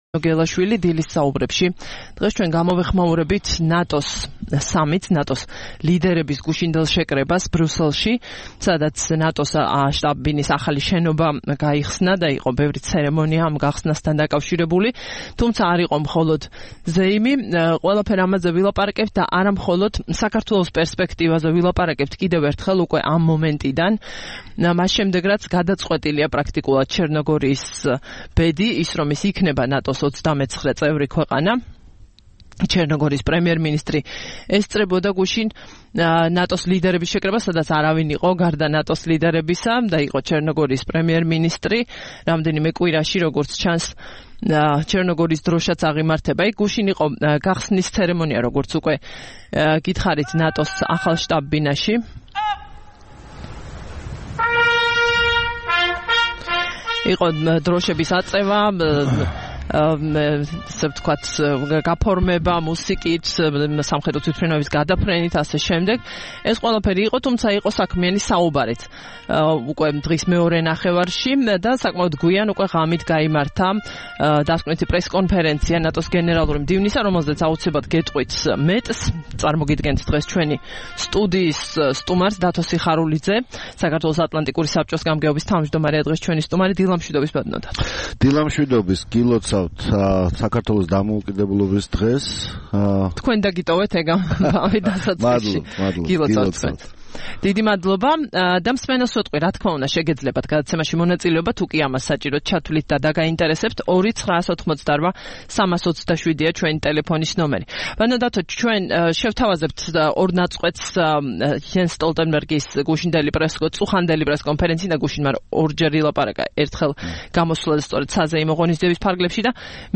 სტუმრად ჩვენს ეთერში: დათო სიხარულიძე